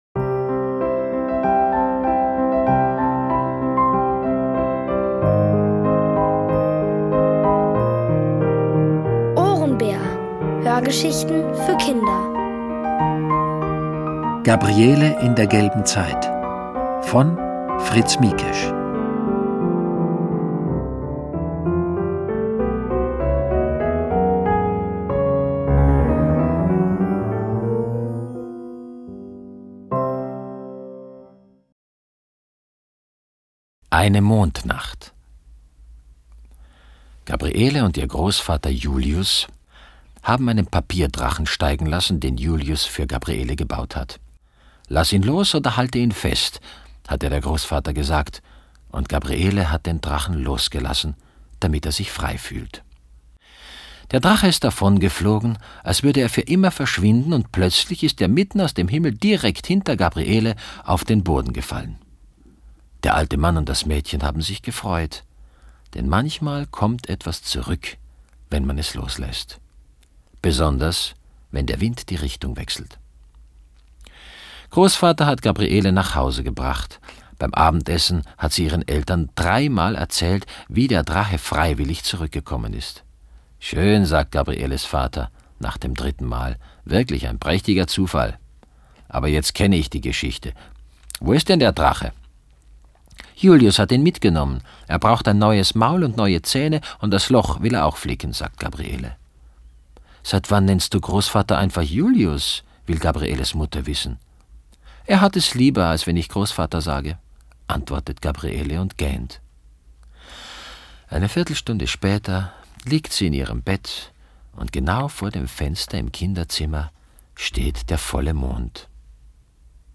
Von Autoren extra für die Reihe geschrieben und von bekannten Schauspielern gelesen.
Es liest: Peter Simonischek.